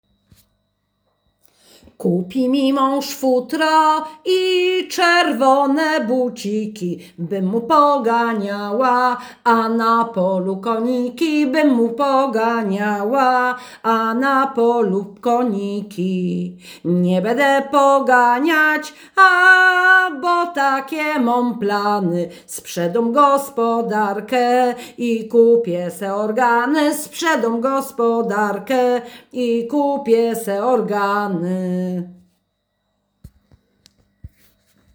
Nagranie współczesne